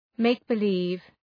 {,meıkbı’li:v}
make-believe.mp3